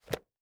04_书店内_点击书本.wav